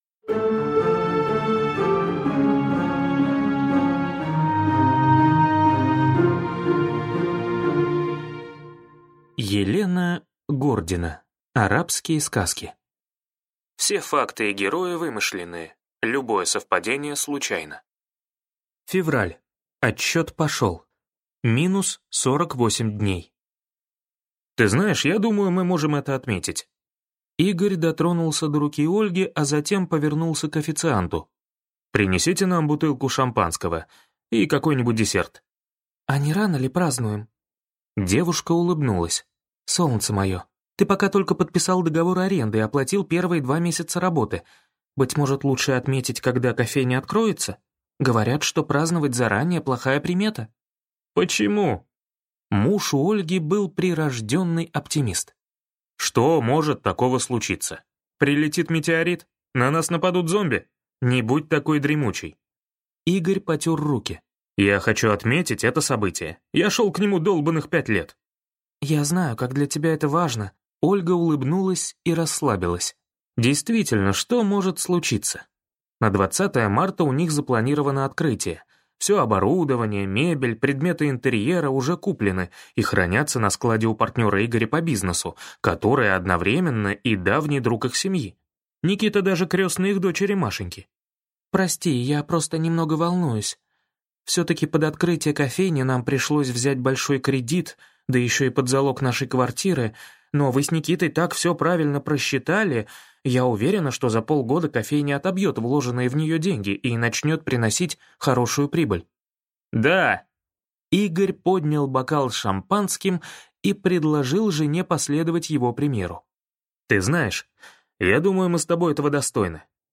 Аудиокнига Арабские сказки | Библиотека аудиокниг